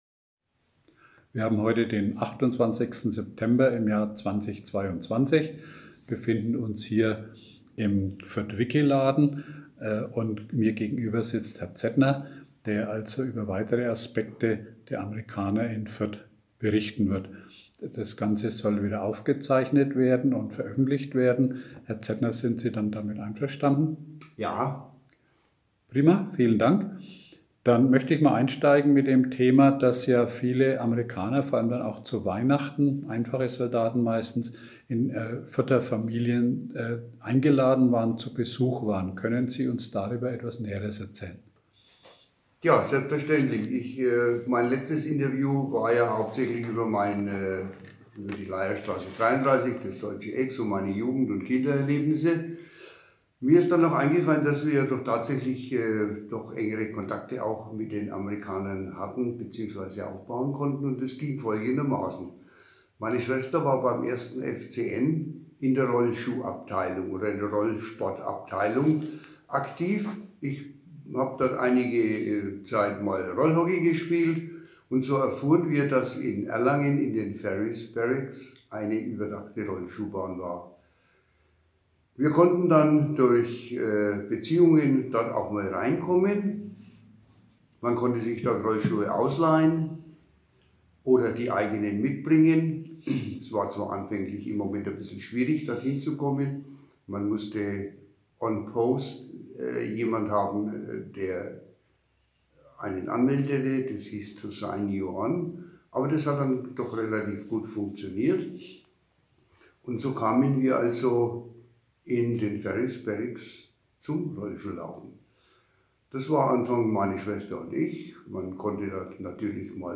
Zeitzeugenberichte
Interview